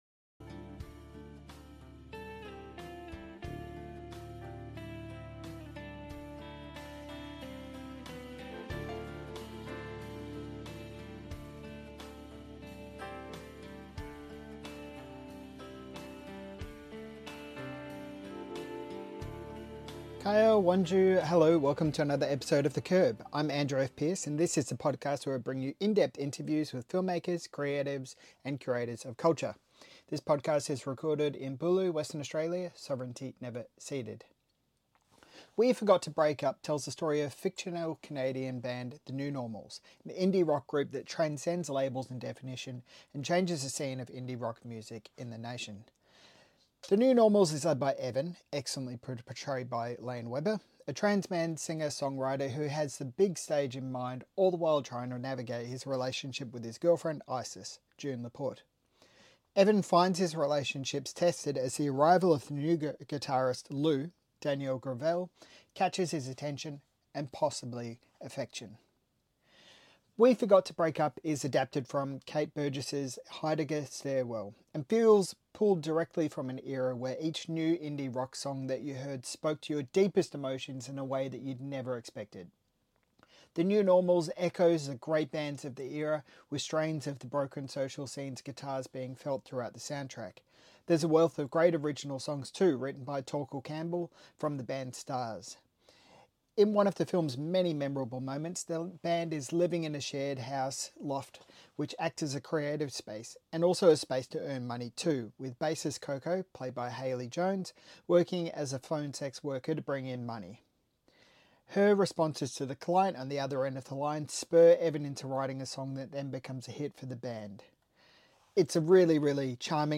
Queer Screen Interview